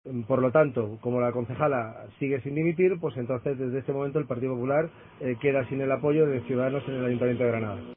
Luis Salvador, portavoz de Ciudadanos anuncia la ruptura del pacto